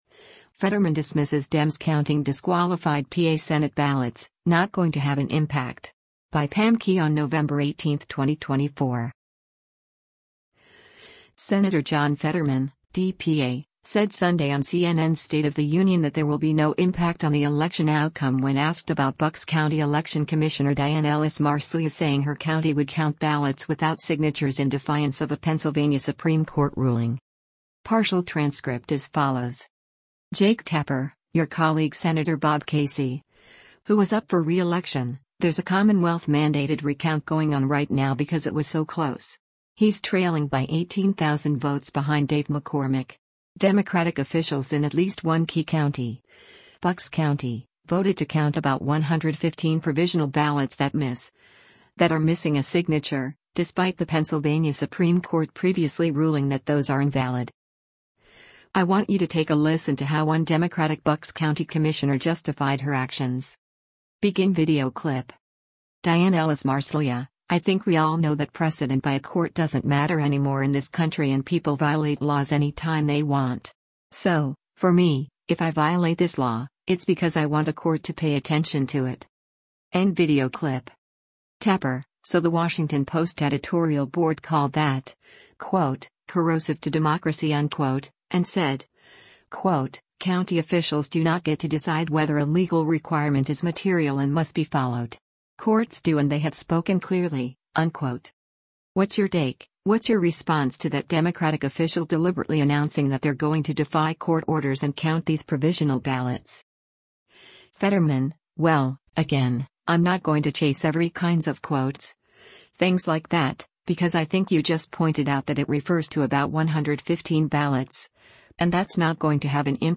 Senator John Fetterman (D-PA) said Sunday on CNN’s “State of the Union” that there will be no impact on the election outcome when asked about Bucks County election commissioner Diane Ellis-Marseglia saying her county would count ballots without signatures in defiance of a Pennsylvania Supreme Court ruling.